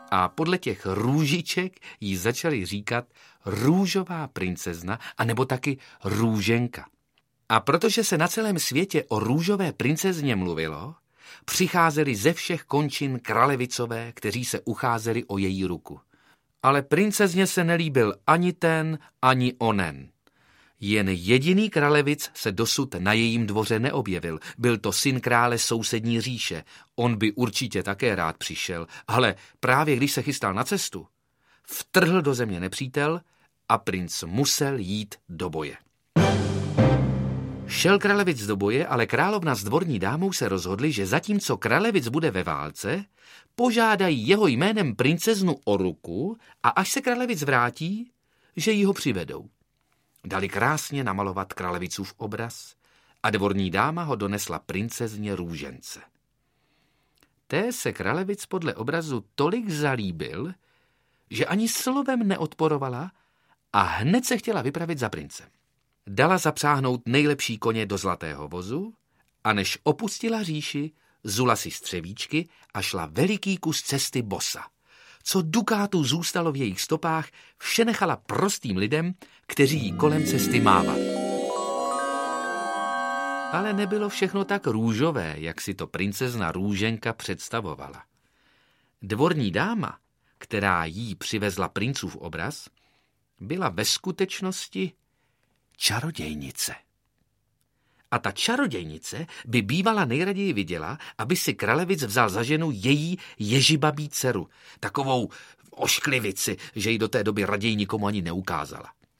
Ukázka z knihy
Jeden z nejpopulárnějších českých herců současnosti, Petr Rychlý, se stal v nahrávacím studiu vypravěčem pohádek z různých koutů světa, které mohou děti nejen pobavit, ale také seznámit s hrdiny a postavami pohádek z Anglie, Filipín, Maďarska či Norska. V celkem devíti pohádkových příbězích oživil Petr Rychlý svým hlasem více než dvě desítky figurek, postav a postaviček a dokázal, že jeskvělým vypravěčem a hercem mnoha hlasů a výrazů.
• InterpretPetr Rychlý